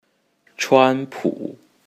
Trump's Chinese Pinyin name is written as chuān pǔ, and pronounced as
chuanpu2.mp3